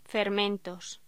Locución: Fermentos